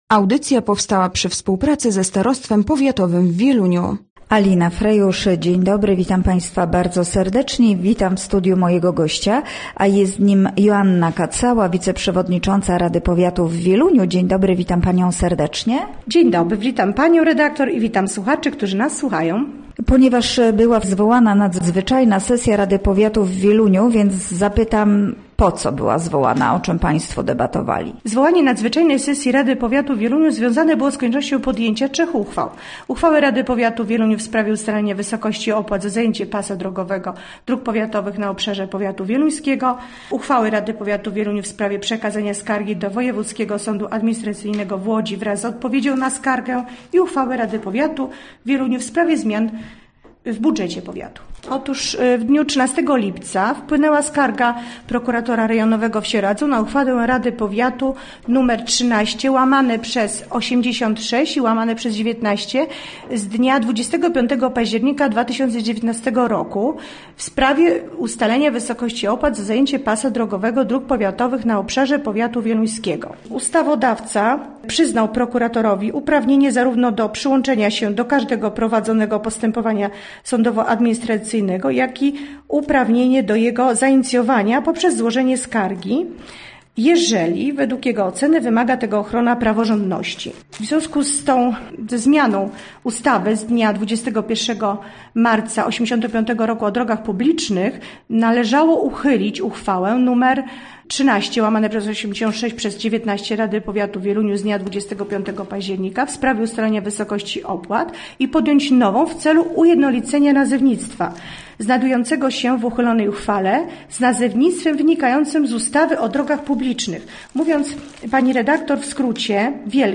Gościem Radia ZW była Joanna Kacała, wiceprzewodnicząca Rady Powiatu w Wieluniu